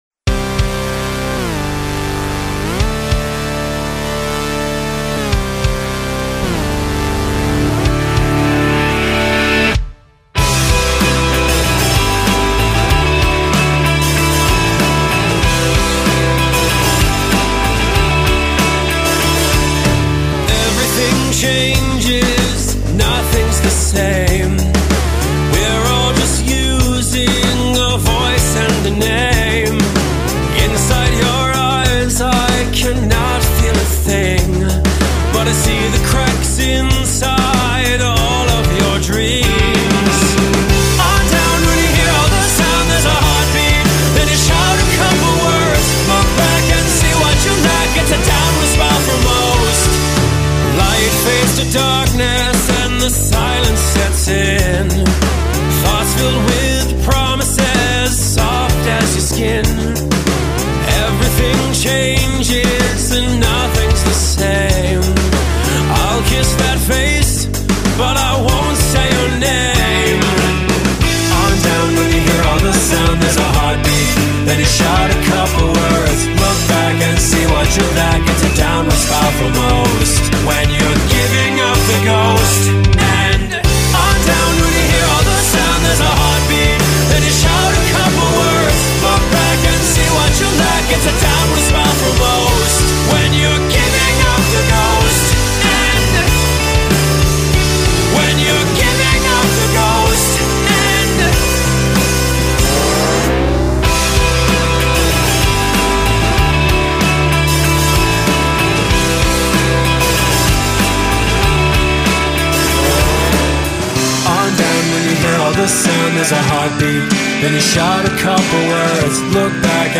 Vocalist
has a very full voice with huge potential